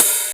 Index of /90_sSampleCDs/Club_Techno/Percussion/Hi Hat
Hat_O_03.wav